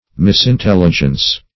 misintelligence - definition of misintelligence - synonyms, pronunciation, spelling from Free Dictionary
Search Result for " misintelligence" : The Collaborative International Dictionary of English v.0.48: Misintelligence \Mis`in*tel"li*gence\, n. 1.